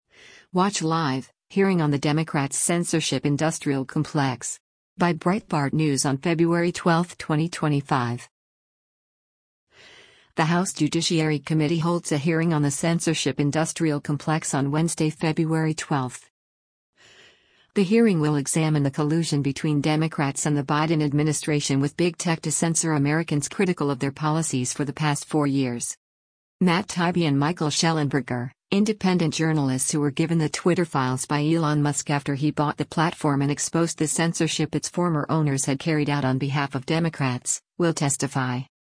The House Judiciary Committee holds a hearing on the “censorship-industrial complex” on Wednesday, February 12.
Matt Taibbi and Michael Shellenberger, independent journalists who were given the Twitter Files by Elon Musk after he bought the platform and exposed the censorship its former owners had carried out on behalf of Democrats, will testify.